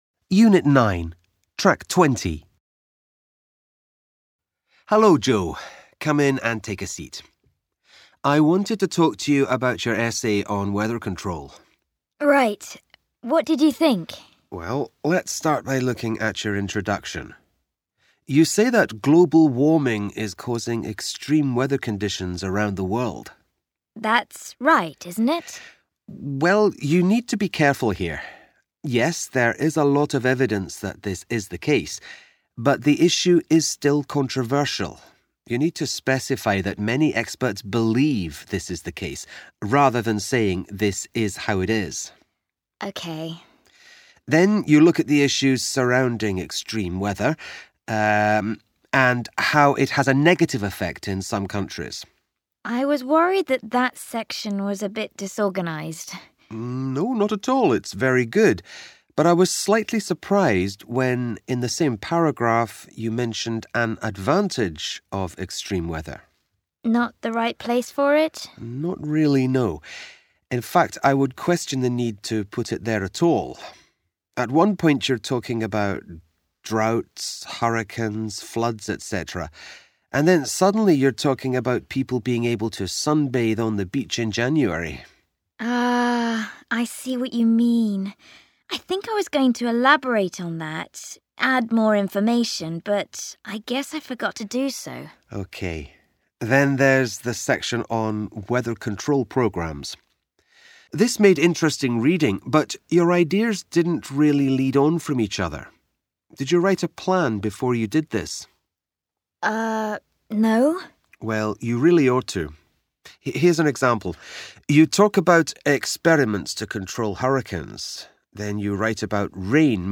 What comment does the tutor make about each part of the student's essay?